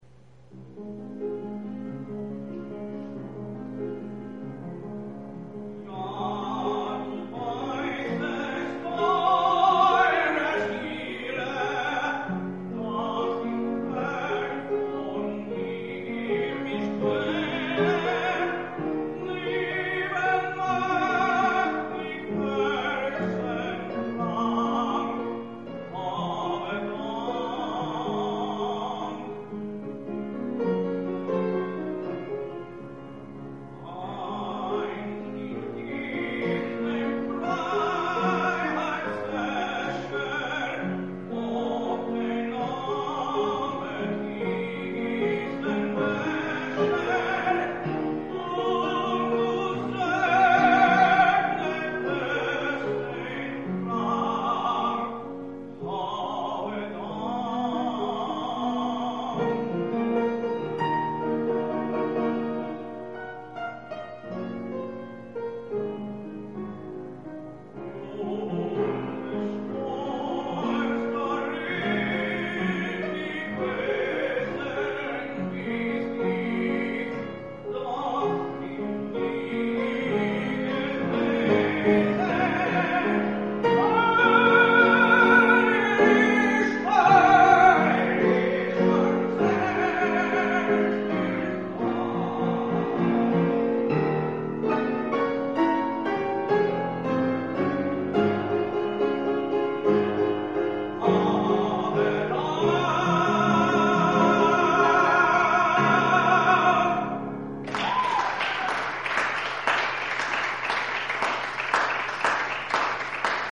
Piano
Zueignung (Dedication), (0.9M) lieder by Richard Strauss.